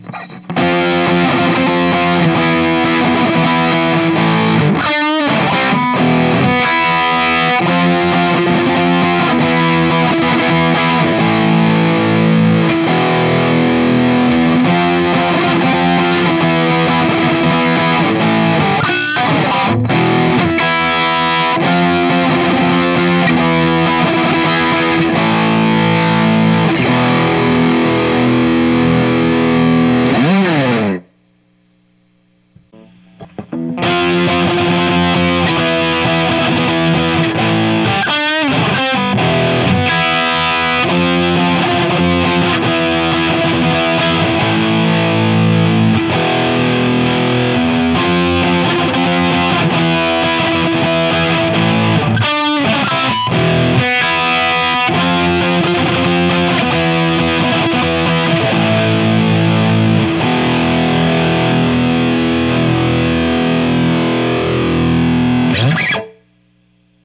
JCM2000とPEAVEY6505ギャビと1960A
最初がJCM2000を6505キャビネットで鳴らす→そして1960Aキャビネットです。
キャビネットでかなり音が変わります。
1960Aはドンシャリキャビなので。ただ音の広がりはPEAVEYのキャビの方が広がる感じがします。